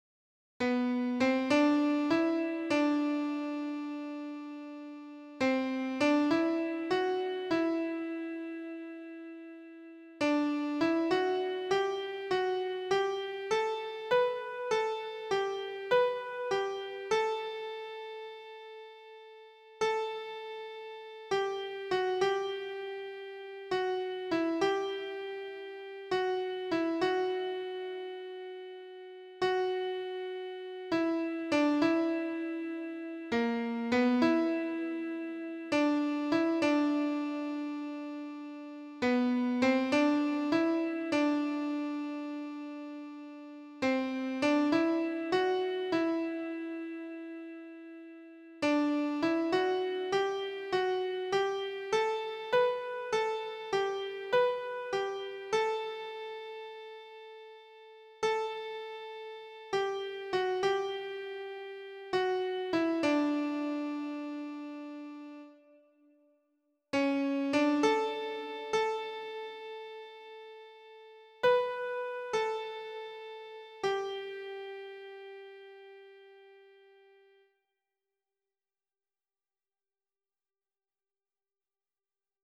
esse_seu_olhar_-_mezzo[50295].mp3